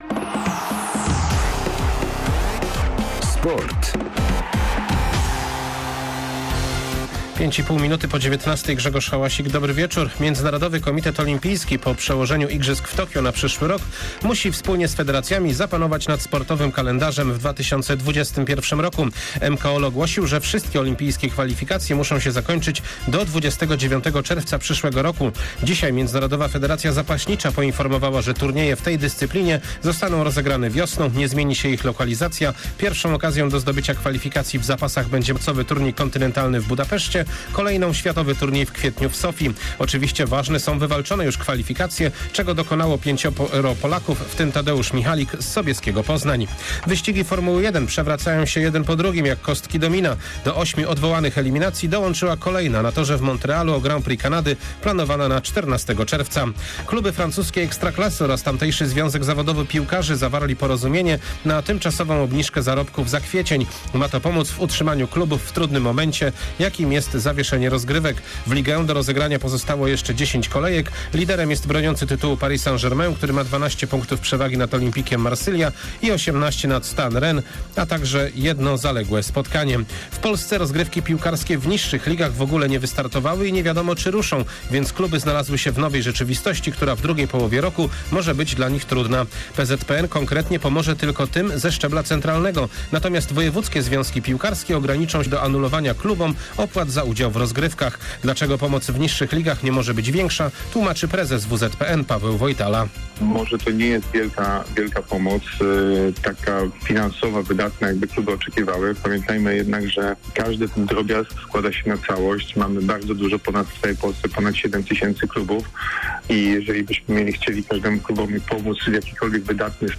08.04. SERWIS SPORTOWY GODZ. 19:05
W serwisie sportowym w środę prezesa Wielkopolskiego Związku Piłki Nożnej - Pawła Wojtalę pytamy, dlaczego wielomilionową pomoc PZPN przygotował dla klubów szczebla centralnego, a nie pomyślano o niższych ligach. Ponadto, dzięki radiowemu archiwum, oddajemy głos sportowym jubilatom...